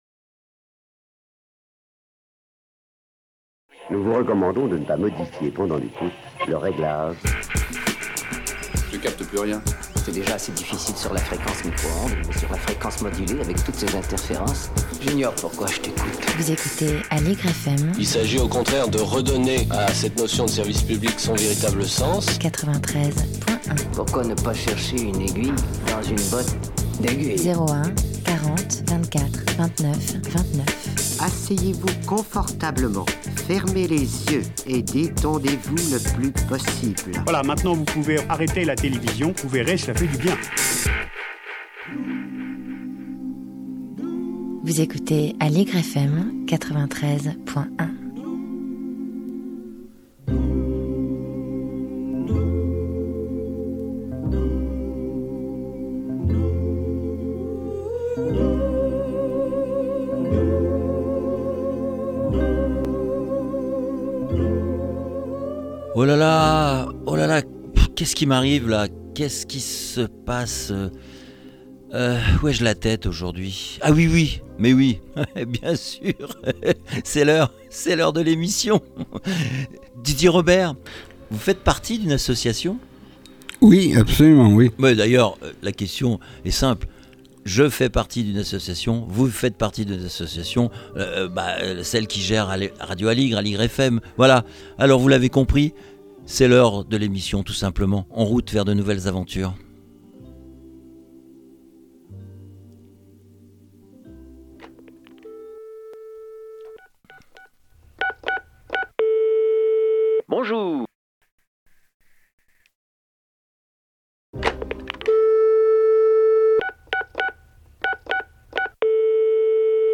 L’Etincelle fait sa rentrée avec le forum des associations du 13e arrondissement de Paris boulevard Auguste Blanqui. Un vrai moment de convivialité sous l’égide de la mairie du 13e arrondissement de Paris ou près de 200 associations participent pour présenter leurs activités. À cette occasion, très présent sur ce forum, nous avons rencontré M. Jérôme Coumet Maire du 13e arrondissement de Paris qui a bien voulu nous accorder un entretien.